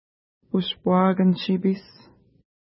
ID: 64 Longitude: -58.7523 Latitude: 52.8380 Pronunciation: uʃpwa:kən-ʃi:pi:s Translation: Pipe River (small) Feature: river Explanation: Named in reference to lake Ushpuakan-nipi (no 63) from which it flows.